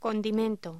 Locución: Condimento